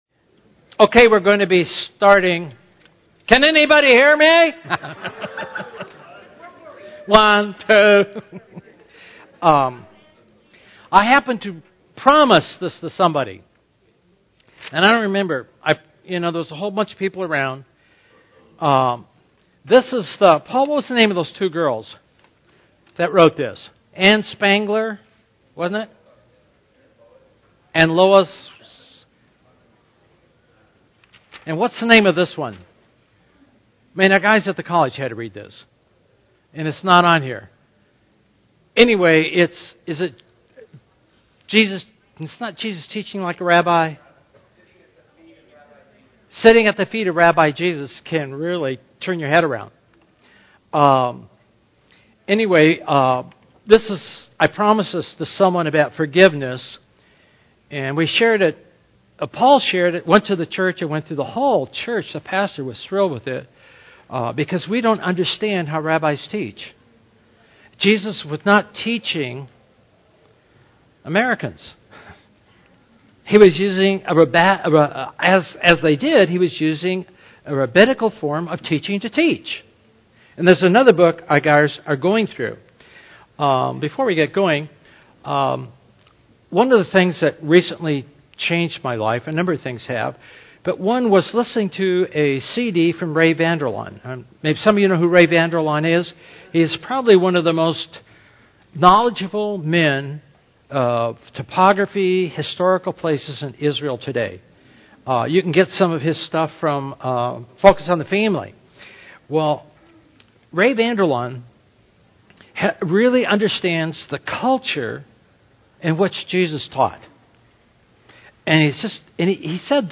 This sermon emphasizes the transformative power of understanding Jesus' teachings in a rabbinical context, particularly focusing on forgiveness and the care God has for each individual. It highlights the impact of realizing that God cares for us personally and how this can change our perspective.